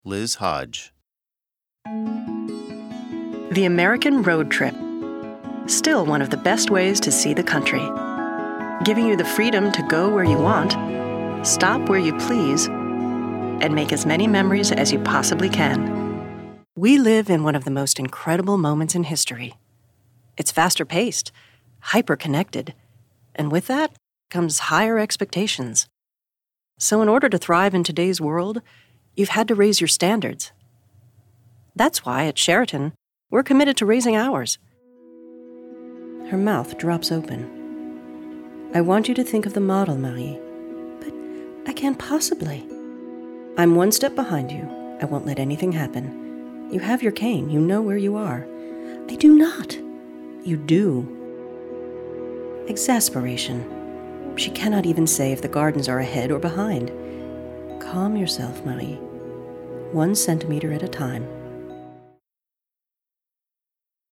VO reel: